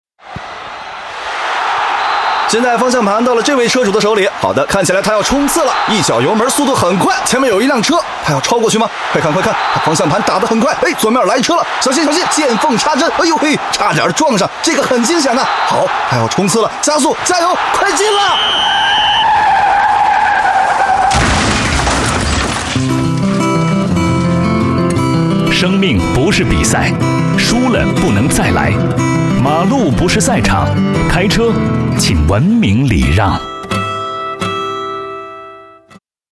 中国交通新闻网首页 > 专题 > 广播类